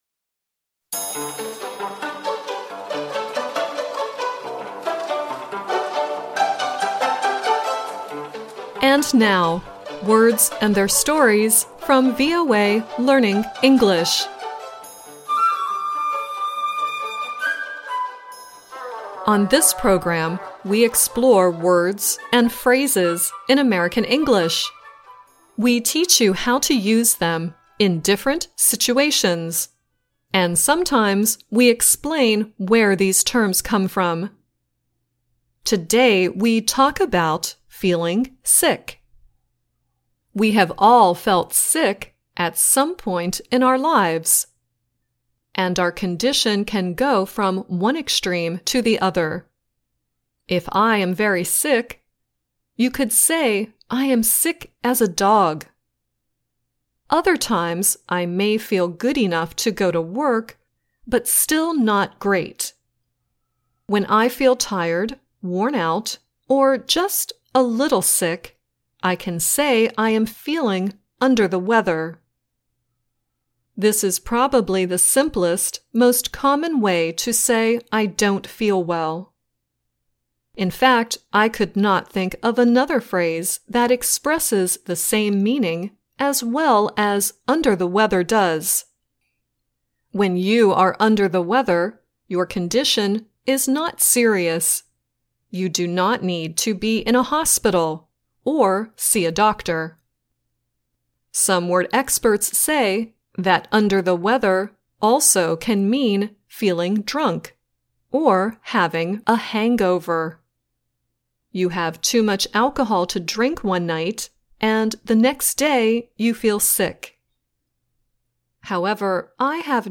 The song at the end is KT Tunstall singing "Under the Weather."